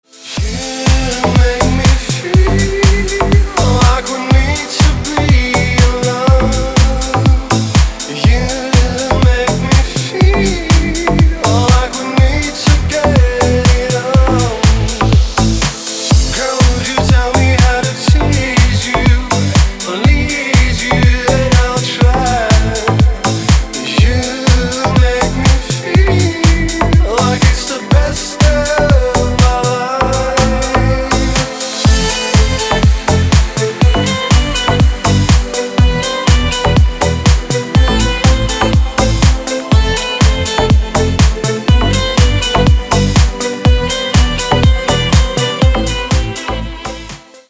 мужской вокал
громкие
dance
Electronic
EDM
электронная музыка
club
Саксофон
Indie Dance